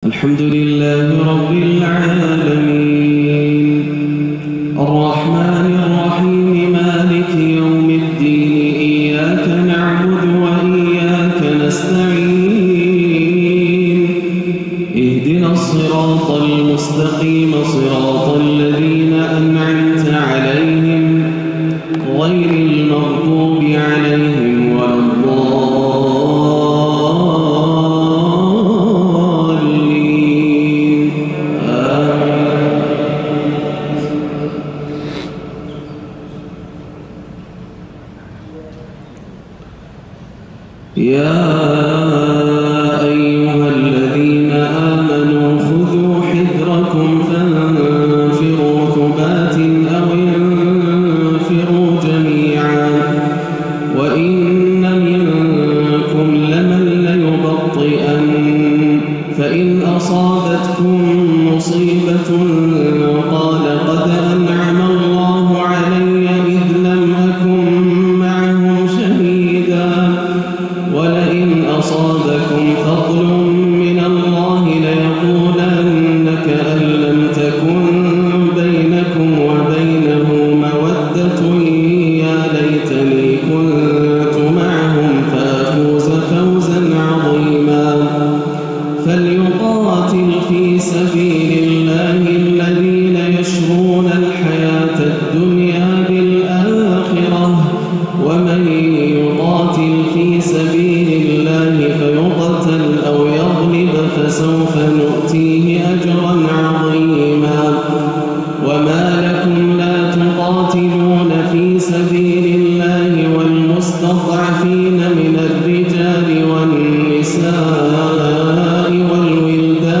صلاة العشاء 2-2-1438هـ من سورة النساء > عام 1438 > الفروض - تلاوات ياسر الدوسري